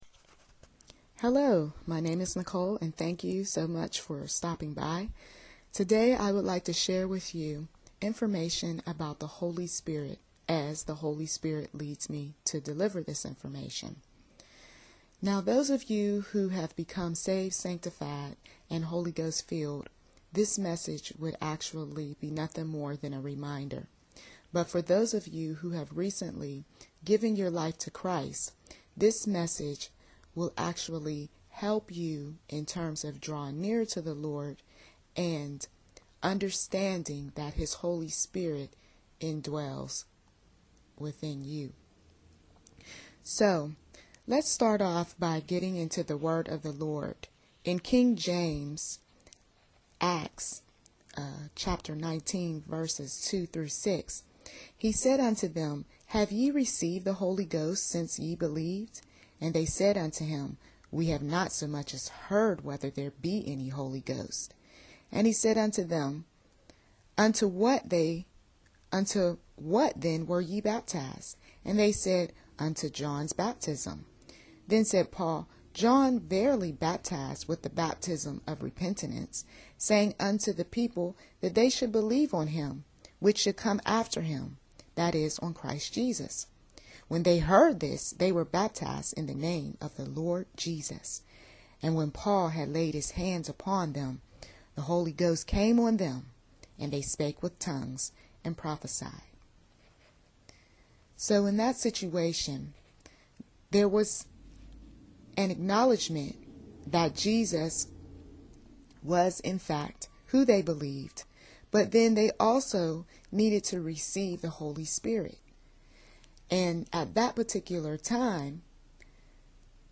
For those interested in learning more about the Holy Spirit left behind after Jesus ascended to Heaven. Inspirational speaker and author